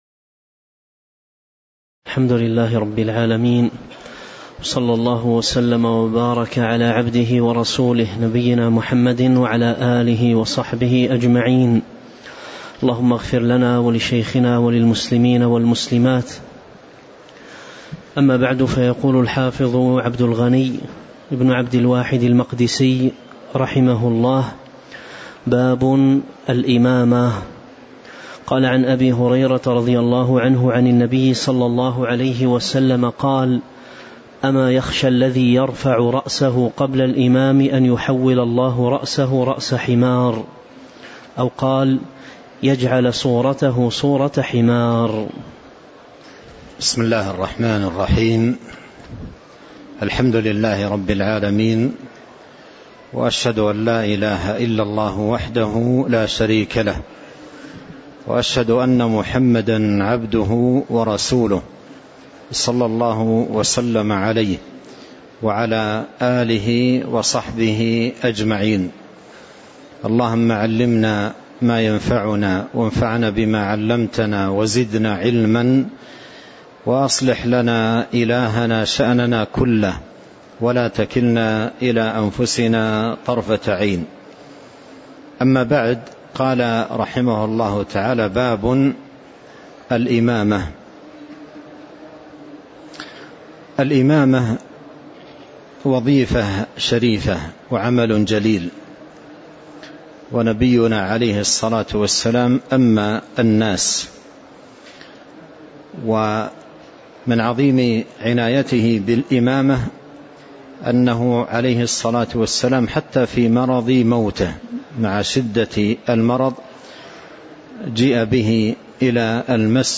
تاريخ النشر ٢٩ ربيع الأول ١٤٤٤ هـ المكان: المسجد النبوي الشيخ: فضيلة الشيخ عبد الرزاق بن عبد المحسن البدر فضيلة الشيخ عبد الرزاق بن عبد المحسن البدر قوله: باب الإمامة (06)